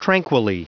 Prononciation du mot tranquilly en anglais (fichier audio)
Vous êtes ici : Cours d'anglais > Outils | Audio/Vidéo > Lire un mot à haute voix > Lire le mot tranquilly